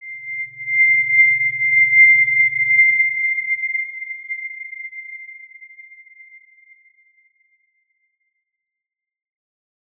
X_Windwistle-C6-mf.wav